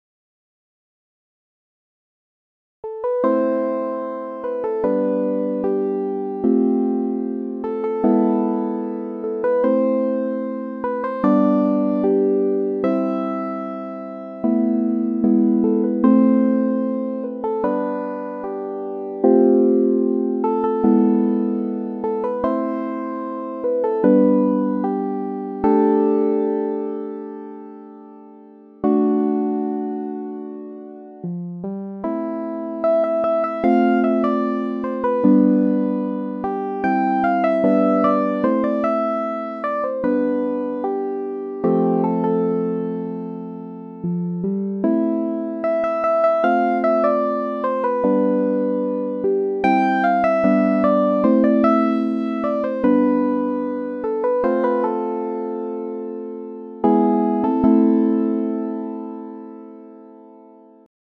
簡易デモ音源はこちら(1.16 MB)